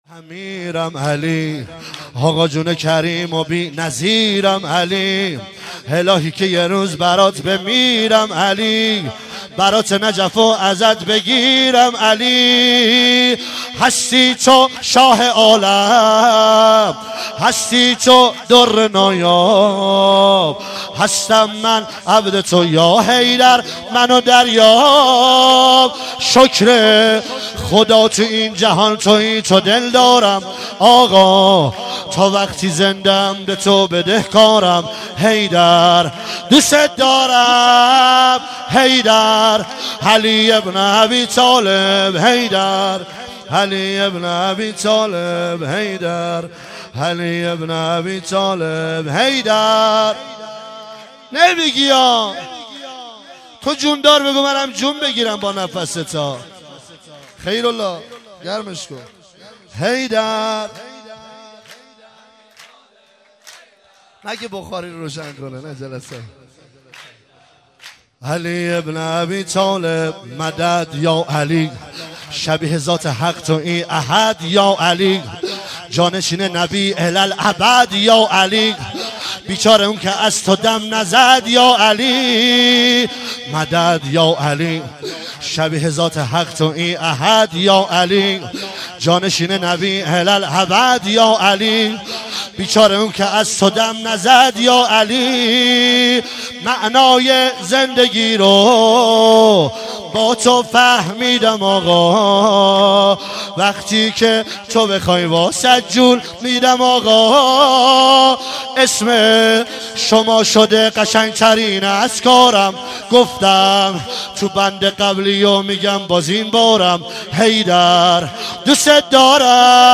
عنوان عید غدیر ۱۳۹۸
مداح
سرود